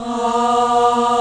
FEM 2 A#2.wav